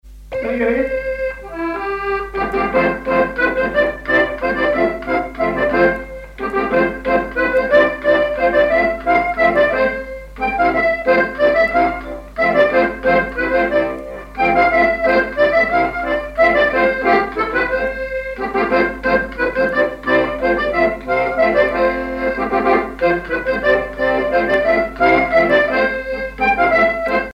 Chants brefs - A danser
danse : mazurka
accordéon diatonique
Pièce musicale inédite